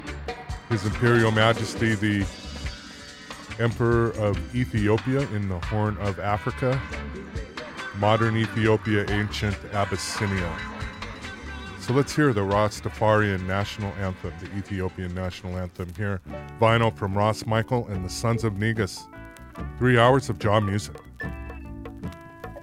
nyahbinghi style drumming